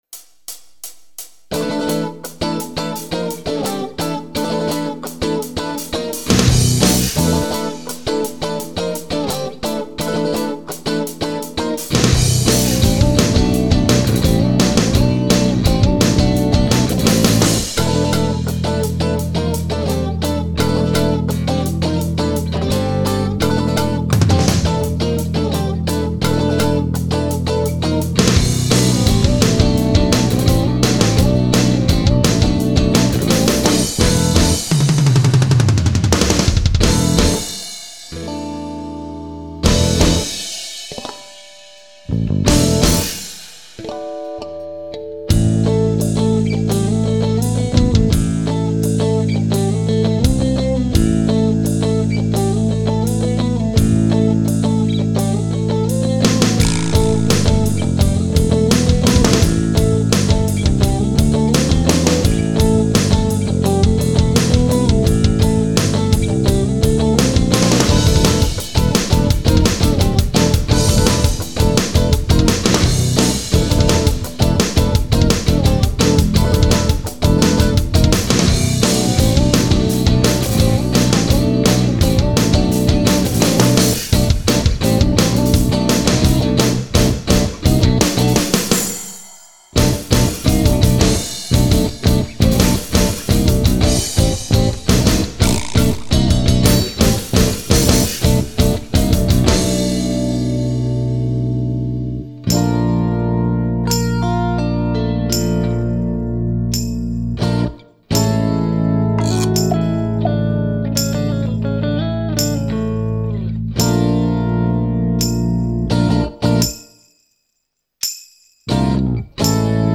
Instrumental, Rock
This is a very rhythm-oriented, short rock song. It uses the classic rock instrument trio – drums, bass and guitar (although the guitar is clean throughout except for a short solo piece where I use a distorted guitar sound).